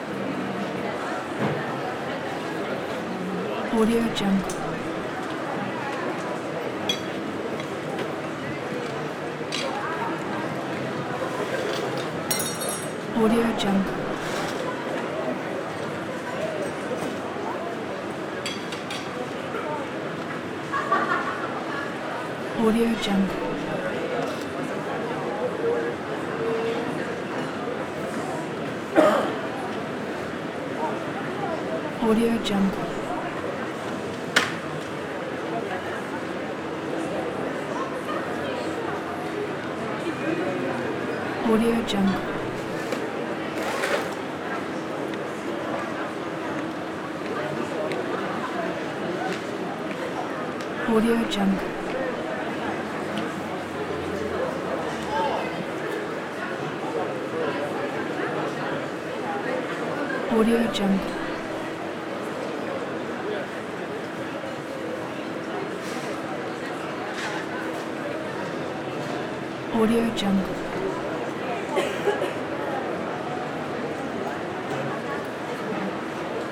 دانلود افکت صوتی شهری
دانلود افکت صدای بازار مواد غذایی
Sample rate 16-Bit Stereo, 44.1 kHz
Looped Yes